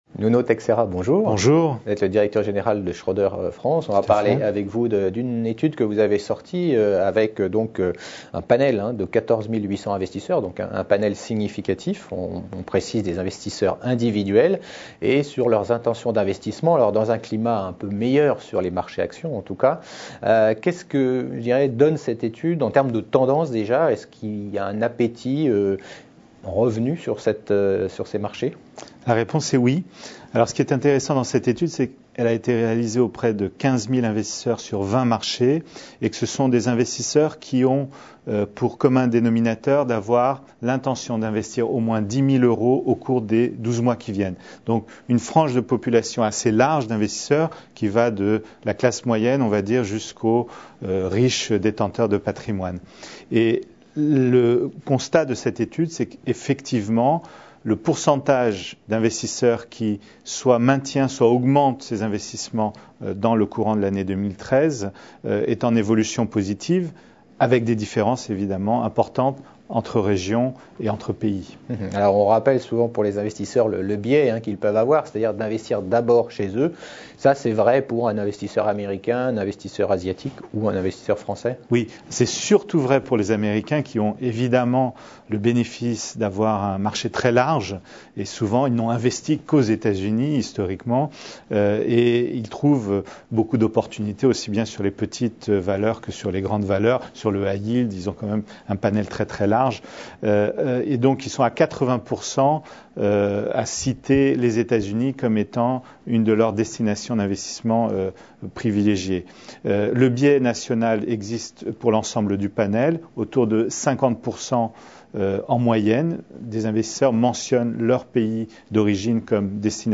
Epargne : Interview